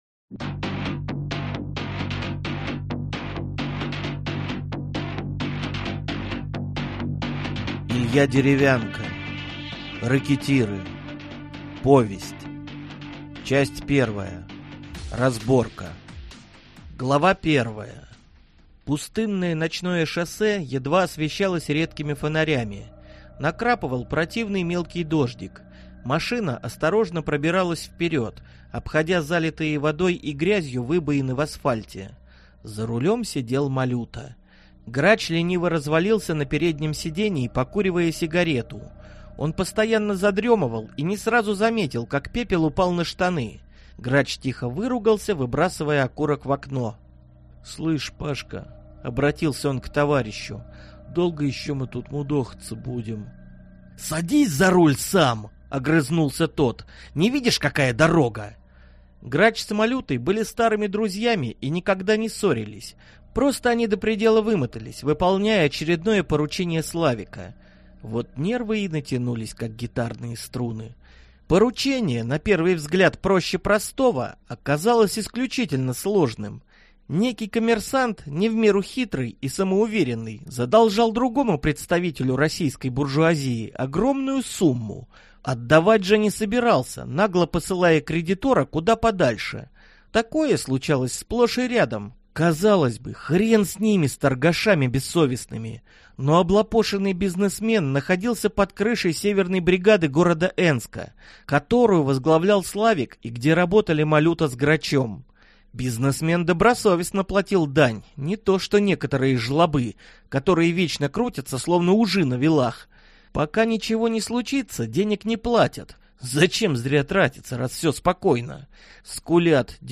Аудиокнига Рэкетиры | Библиотека аудиокниг